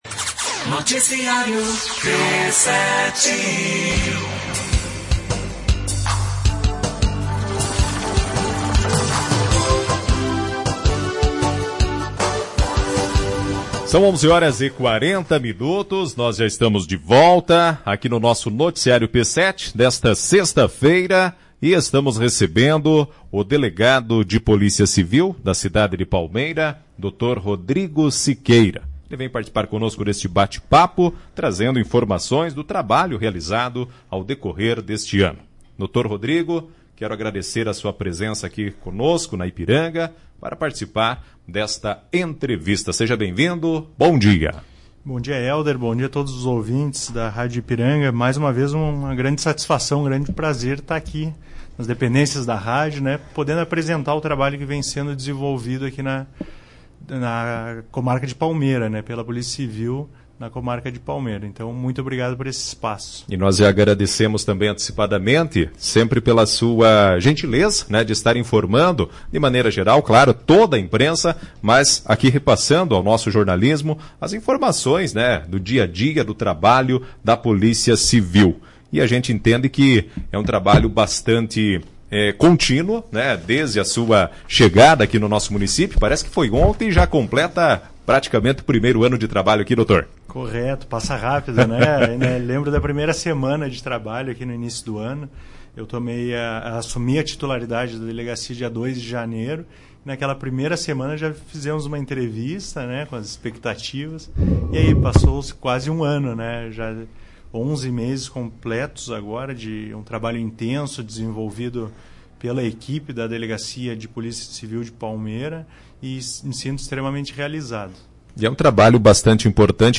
O Delegado Dr. Rodrigo Siqueira, responsável pela 40ª Delegacia Regional de Polícia Civil de Palmeira, compartilhou os resultados e ações do trabalho desenvolvido ao longo do ano de 2023 em uma entrevista à Ipiranga FM.
entrevista-dr-rodrigo-siqueira.mp3